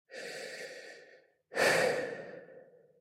lowoxygen.ogg